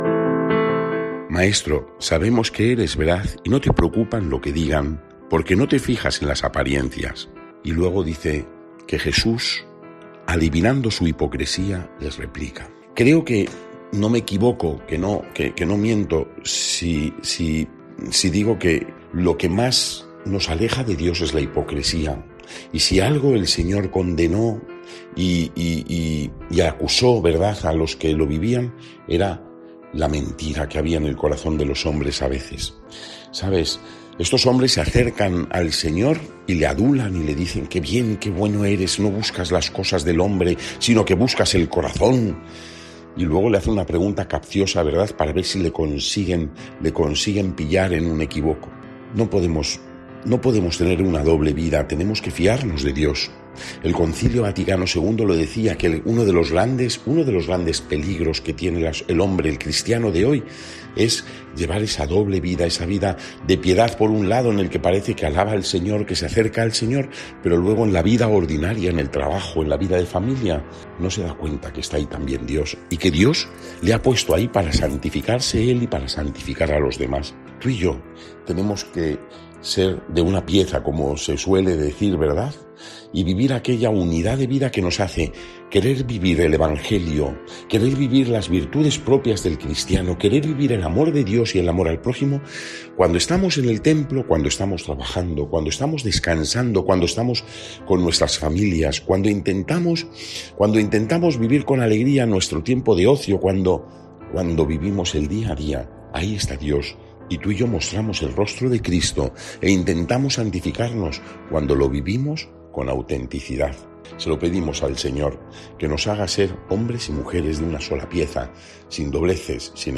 Evangelio